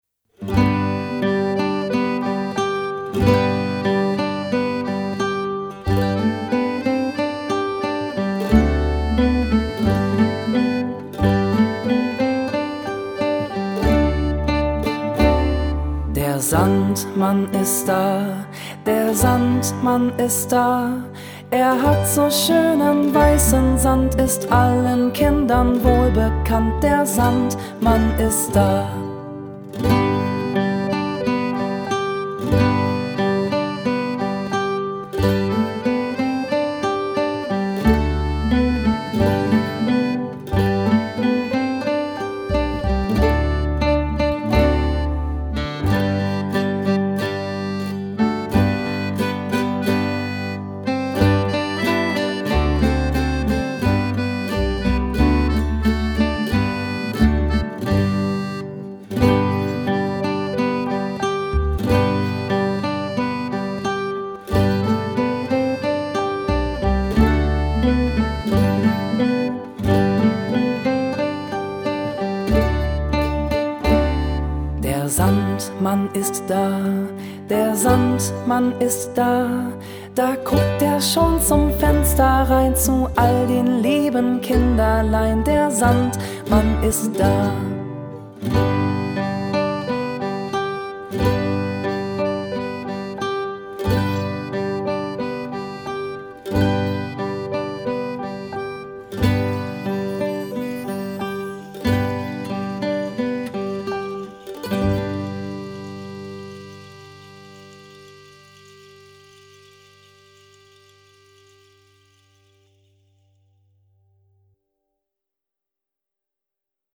Schlagworte Einschlafen • Kinderlieder • Schlaflieder